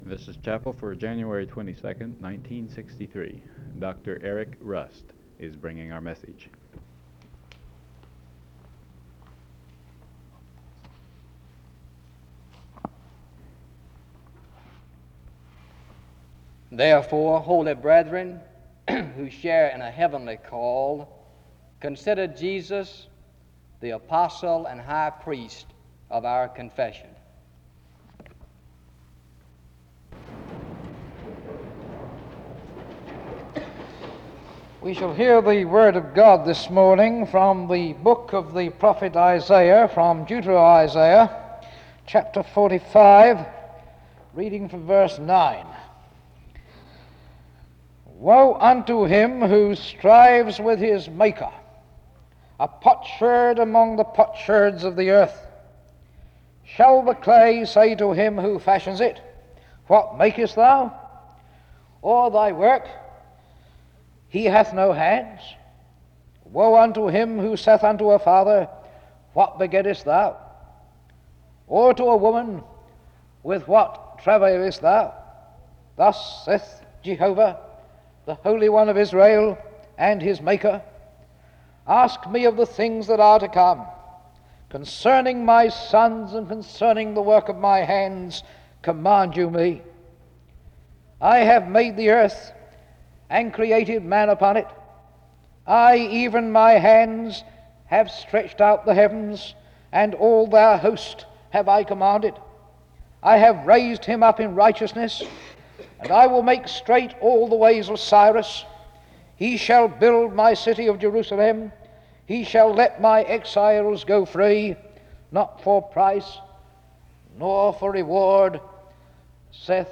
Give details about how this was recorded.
The service begins with the reading of the source text, Isaiah 45:9-17, from 0:16-3:04. Location Wake Forest (N.C.)